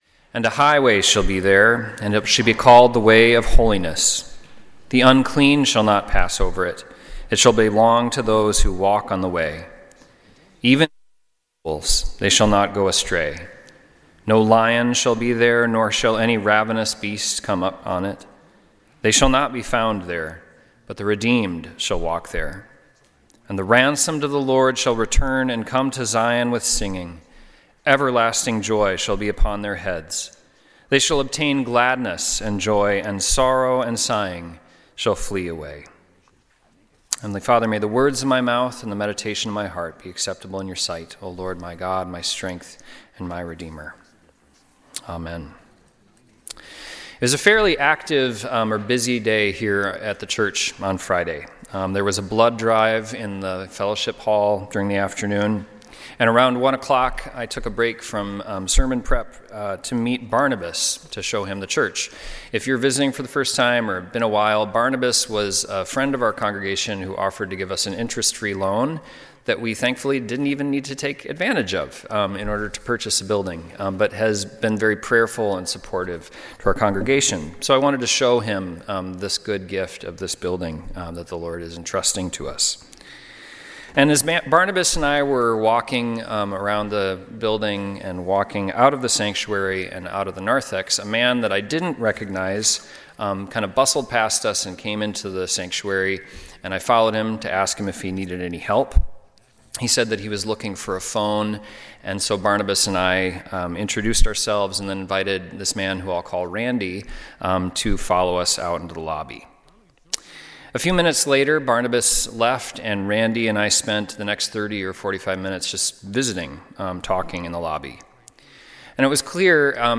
Sunday Worship–Dec. 14, 2025
Sermons